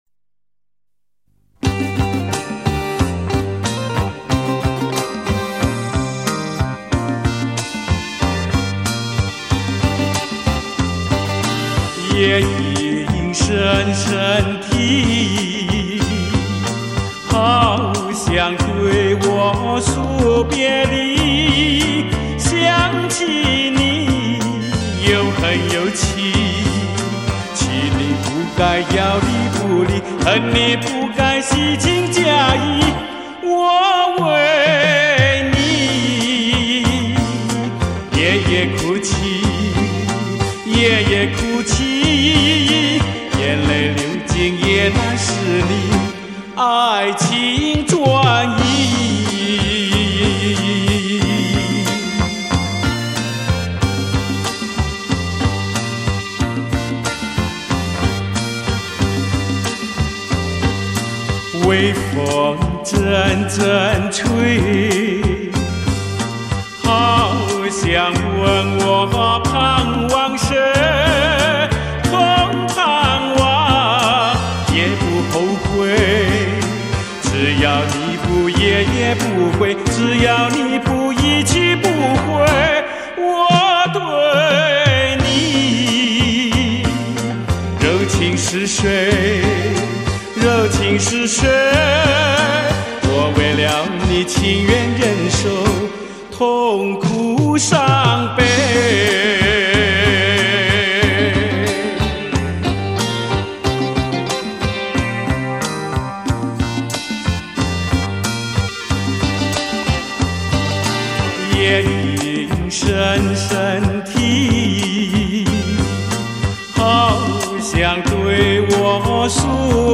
经典哭腔